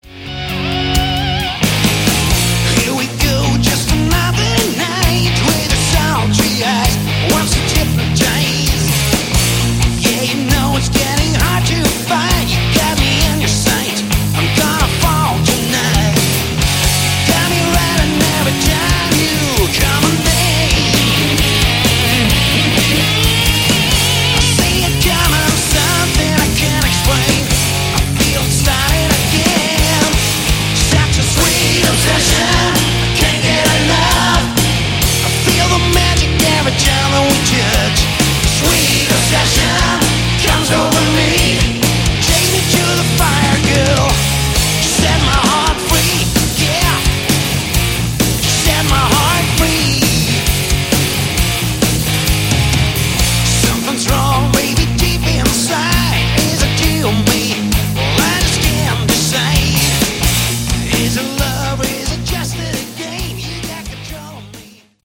Category: Melodic Hard Rock
vocals, guitar
bass
drums